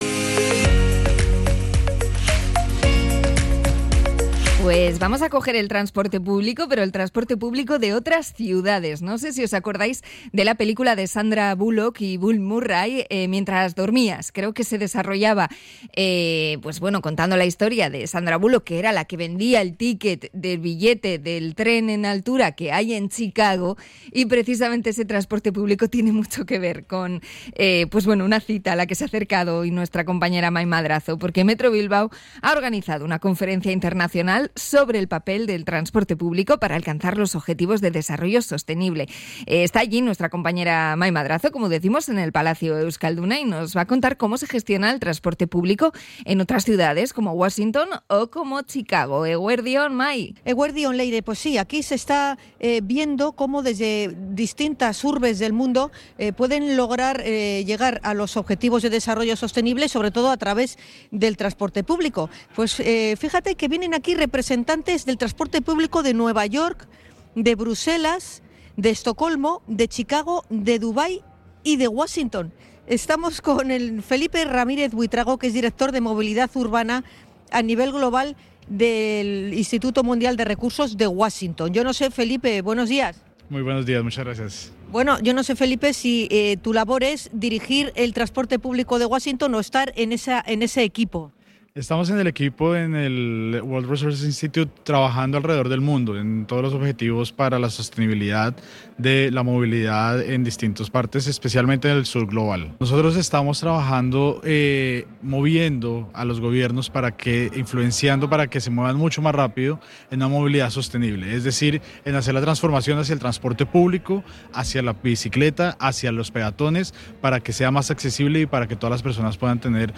Hablamos con dos expertos en transporte de Washington y Chicago